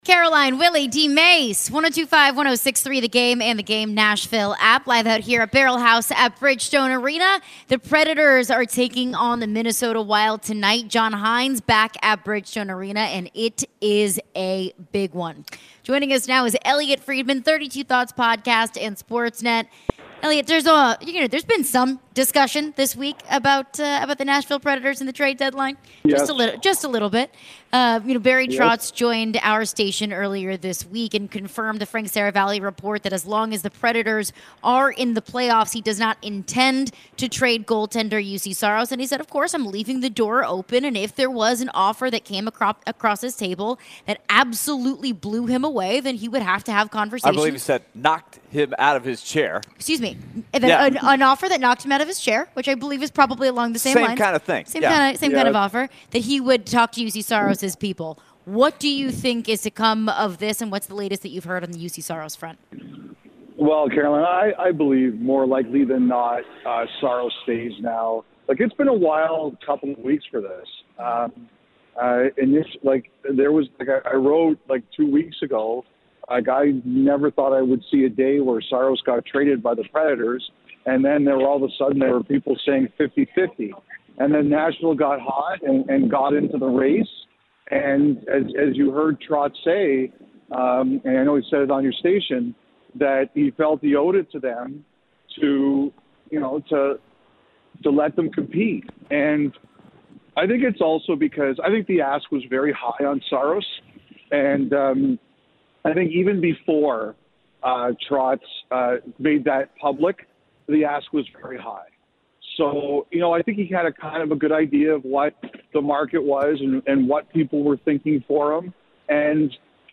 talk with Elliotte Friedman with Sportsnet Hockey Night in Canada